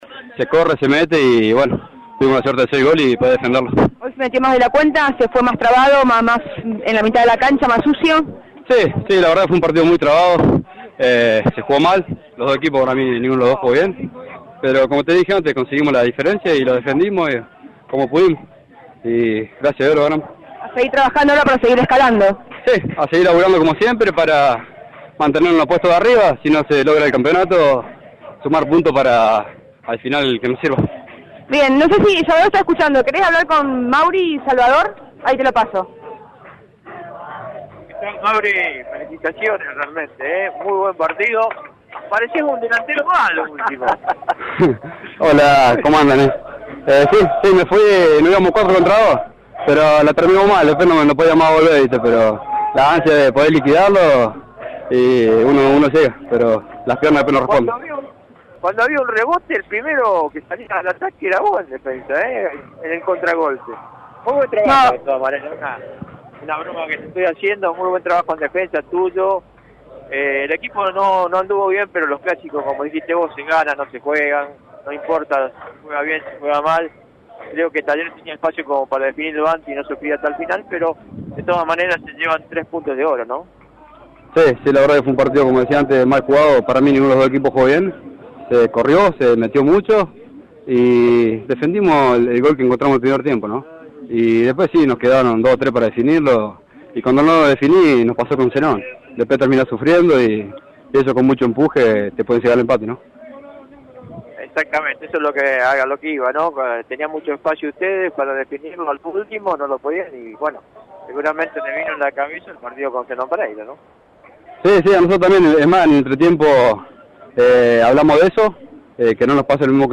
Fue transmisión de la radio